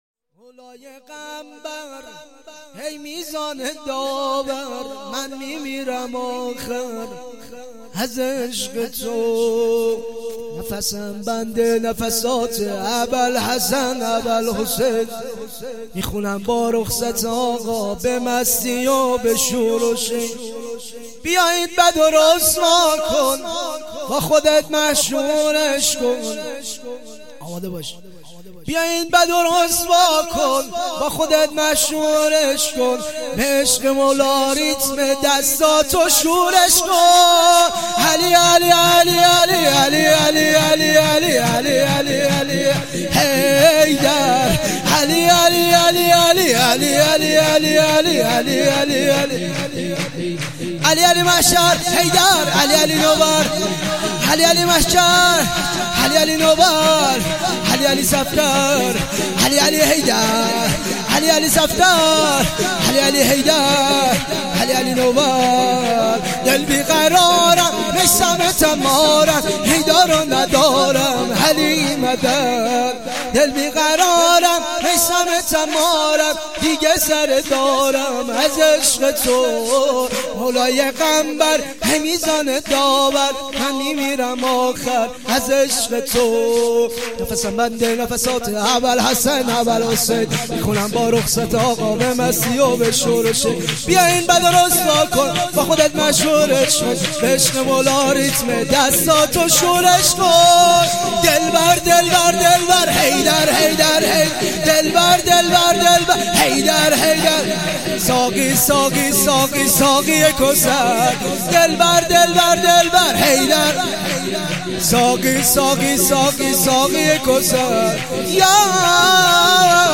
سرود و مدح